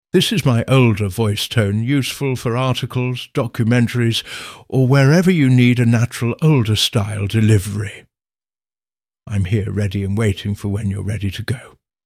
Male
Adult (30-50), Older Sound (50+)
Medical Narrations